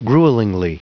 Vous êtes ici : Cours d'anglais > Outils | Audio/Vidéo > Lire un mot à haute voix > Lire le mot gruelingly
Prononciation du mot : gruelingly